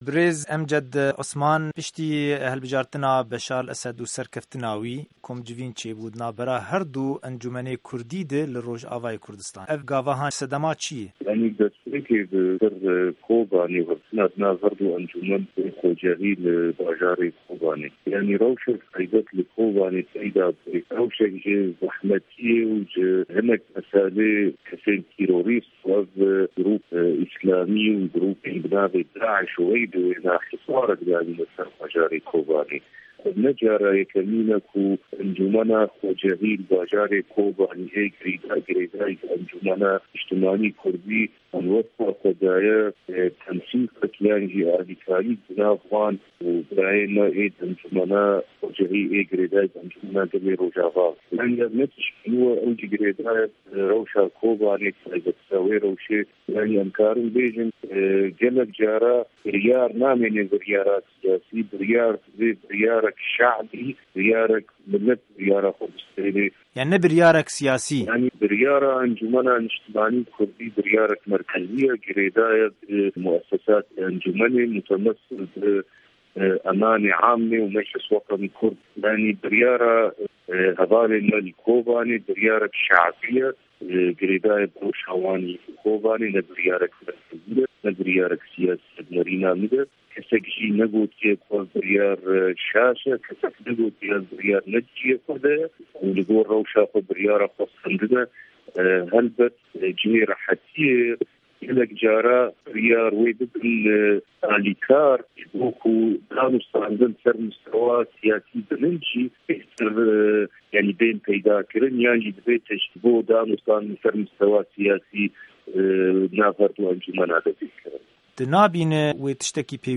Hevpeyvina